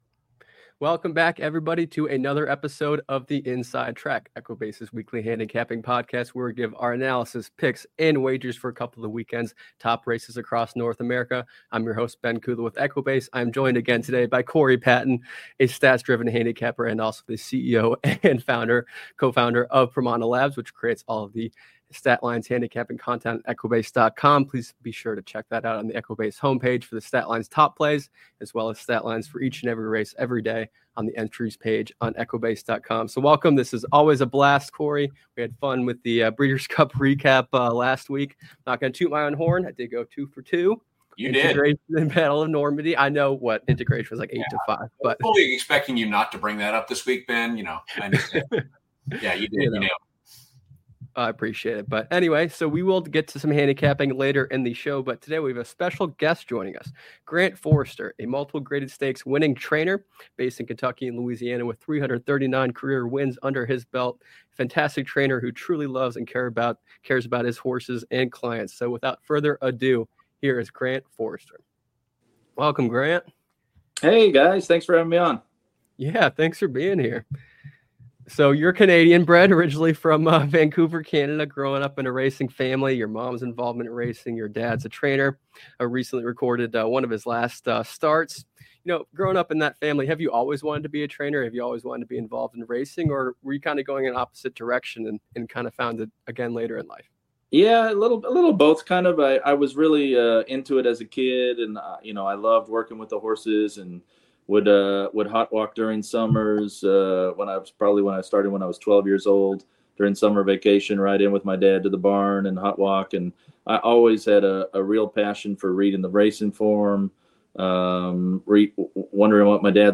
Don't miss this in-depth conversation!